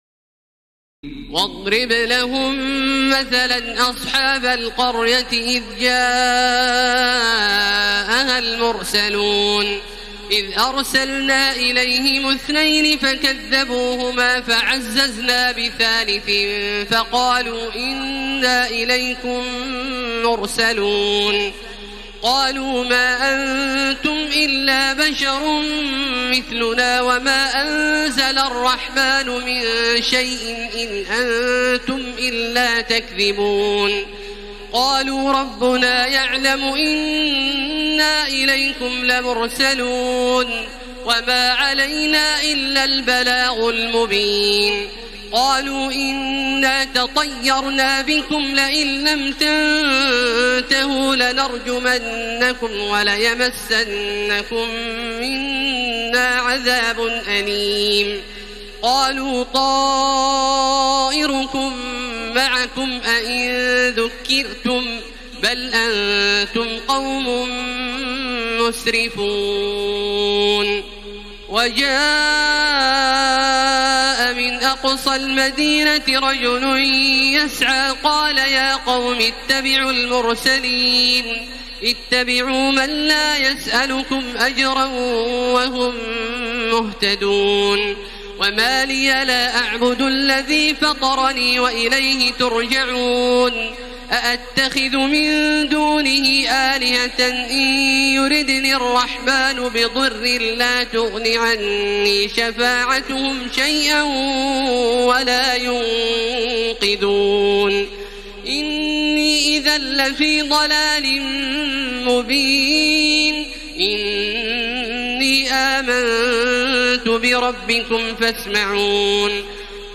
تراويح ليلة 22 رمضان 1433هـ من سور يس (13-83) والصافات(1-138) Taraweeh 22 st night Ramadan 1433H from Surah Yaseen and As-Saaffaat > تراويح الحرم المكي عام 1433 🕋 > التراويح - تلاوات الحرمين